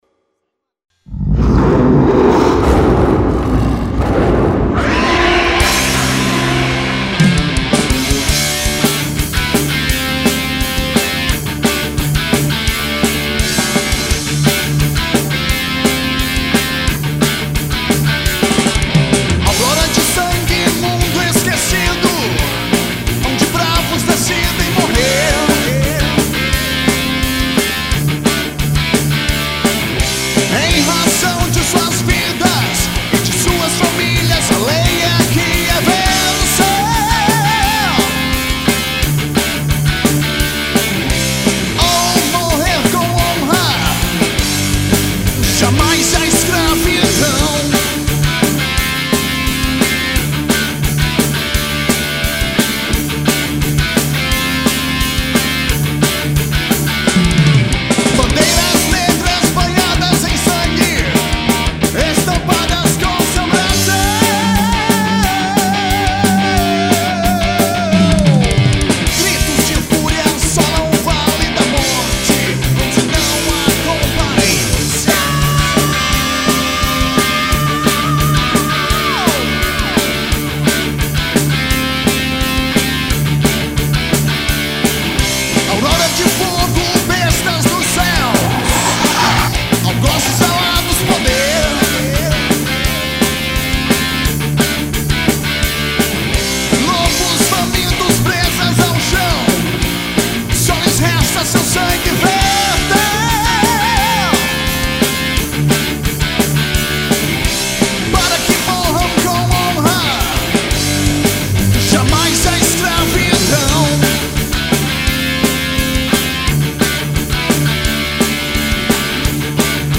EstiloHeavy Metal